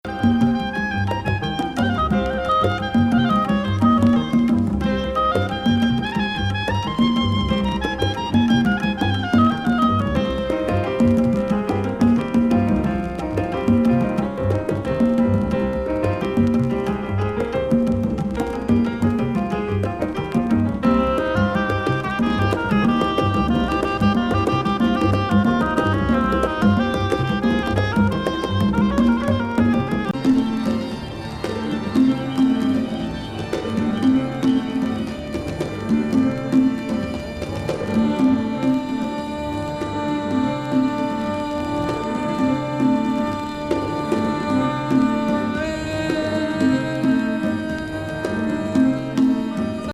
ビヨンビヨンなパンキッシュ電子＋ガレージィなヤッツケ感がなんとも良！